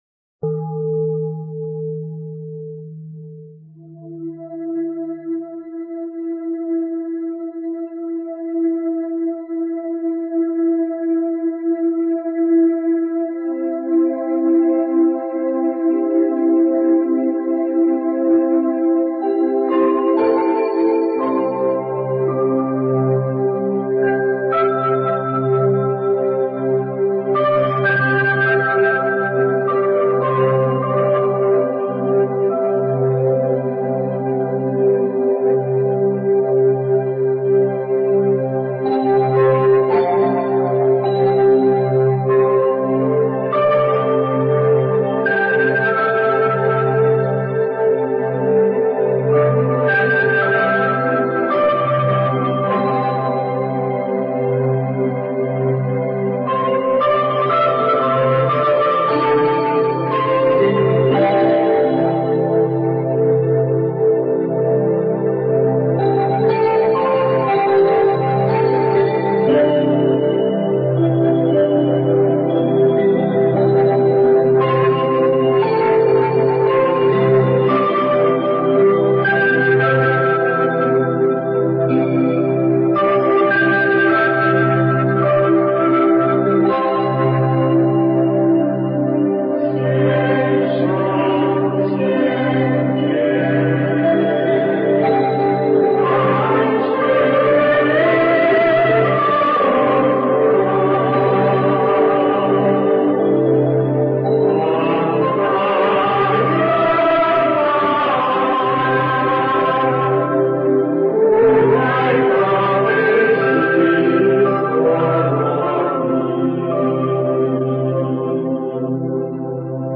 这个《百人合唱大悲咒》最早的时候师父是用气场用图腾看过的，里边一百多个人一起唱，实际上不止一点，多呢，一百几十个人，但是这些人的气场非常好，而且都是很善良的人。
实际上我当时觉得这个《百人合唱大悲咒》来的时候，不知道你们查得到查不到，好像是福建一个什么庙里念的。